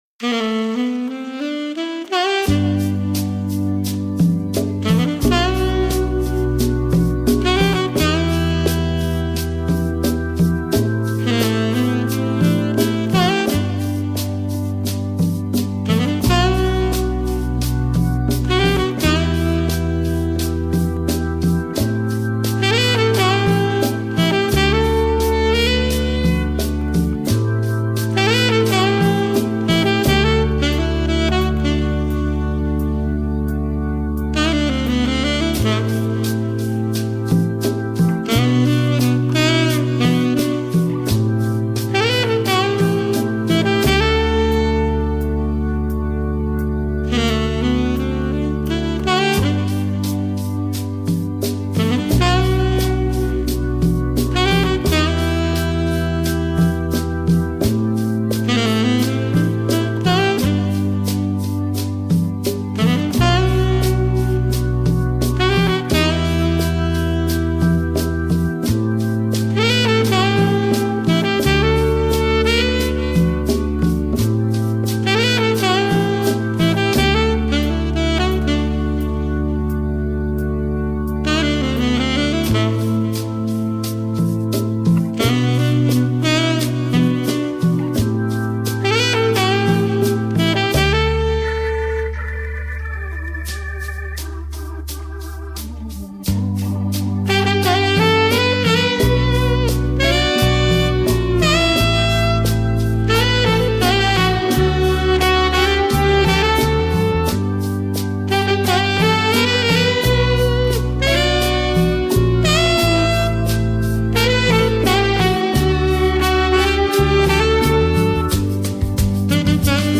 SAX - Latino